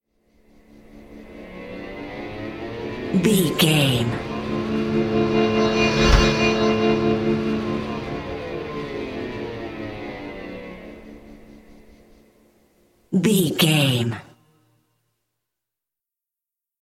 Thriller
Aeolian/Minor
Slow
drum machine
synthesiser
electric piano
electric guitar
ominous
dark
suspense
haunting
creepy
spooky